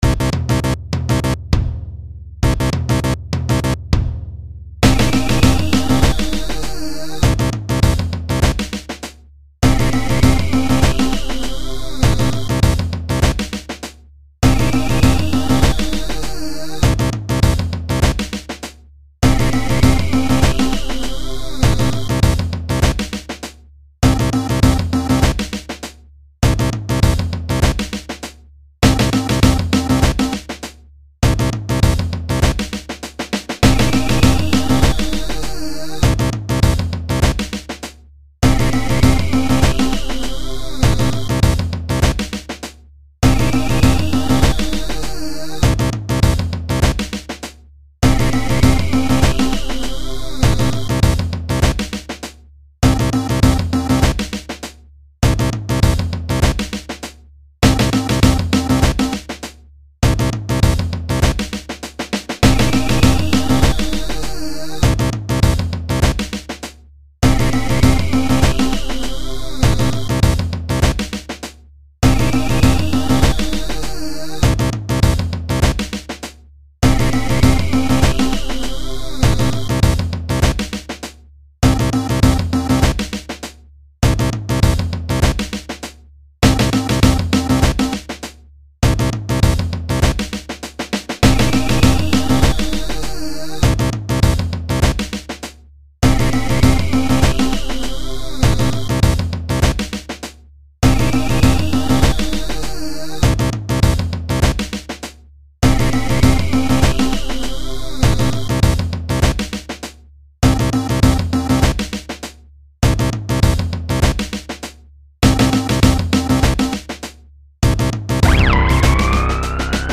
In 2002, I recorded some pieces on my computer, using Fruity Loops. Here are three that are still worth listening to (that is, if you can appreciate somewhat disturbing music!).